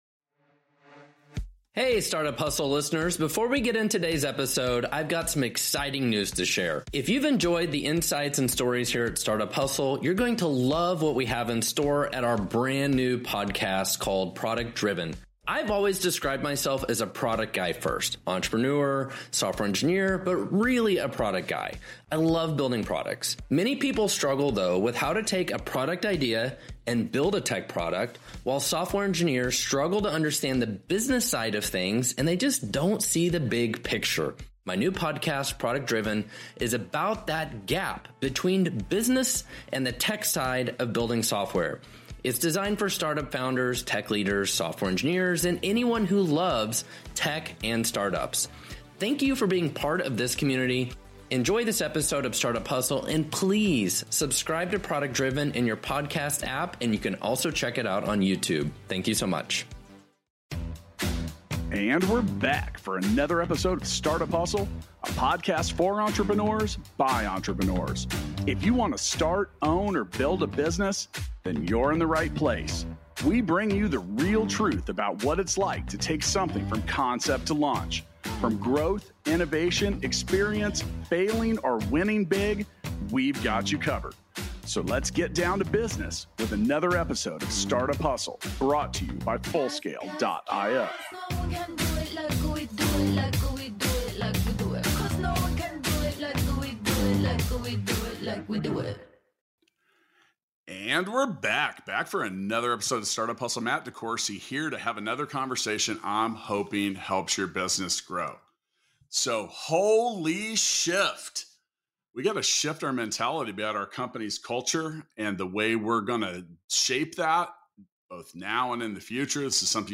for an insightful conversation on the art of transforming your company's culture.